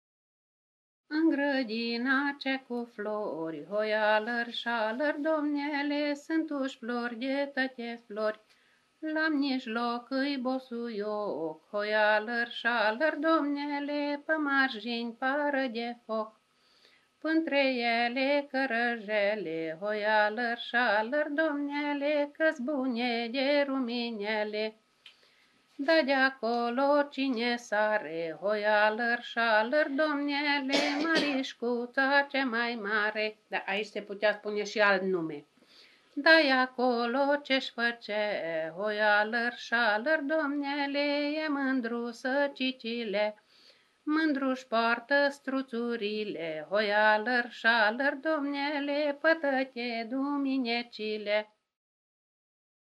Colindă
Motiș
Vocal